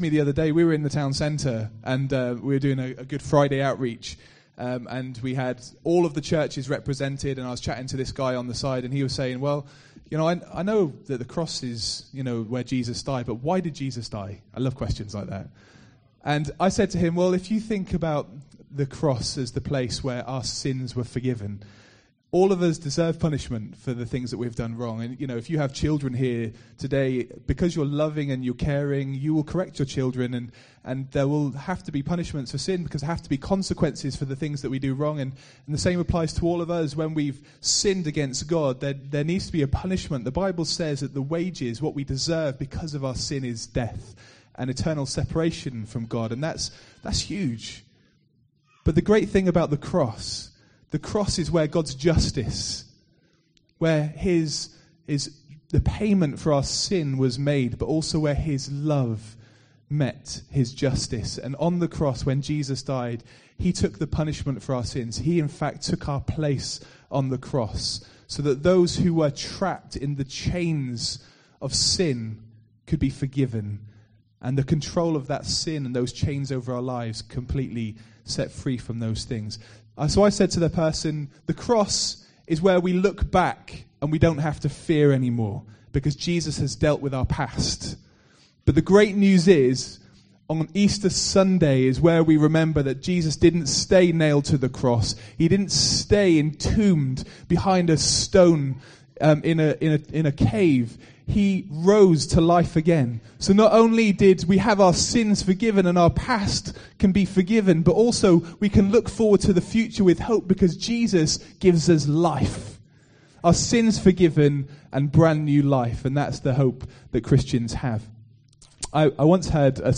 The answer is that Christians worship a God who is alive! The sermon focusses on John 20: 1-18 where Mary, Peter and John shockingly discover that the tomb is empty.